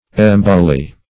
Search Result for " emboly" : The Collaborative International Dictionary of English v.0.48: Emboly \Em"bo*ly\, n. [Gr.